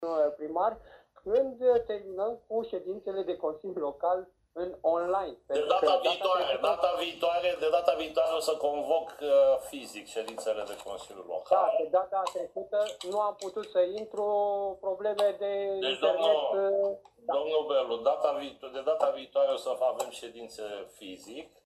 Cel puțin așa a anunțat, astăzi, primarul Vergil Chițac, la finalul întrunirii online